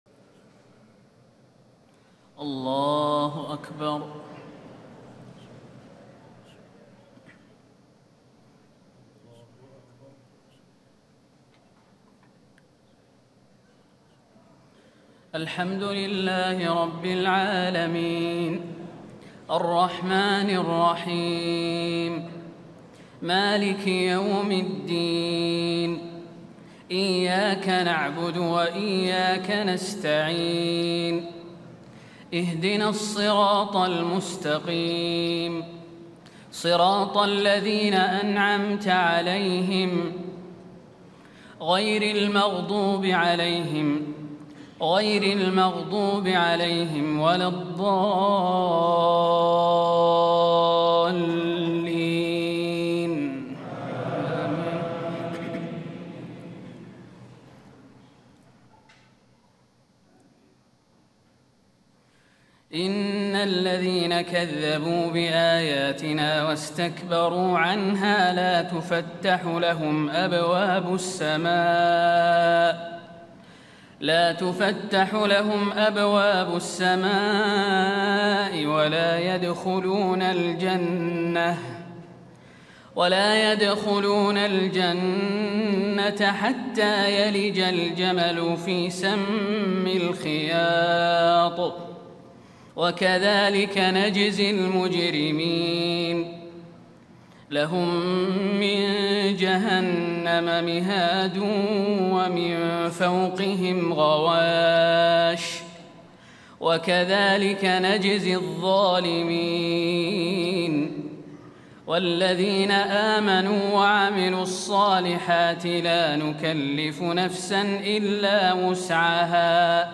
تهجد ليلة 28 رمضان 1436هـ من سورة الأعراف (40-93) Tahajjud 28 st night Ramadan 1436H from Surah Al-A’raf > تراويح الحرم النبوي عام 1436 🕌 > التراويح - تلاوات الحرمين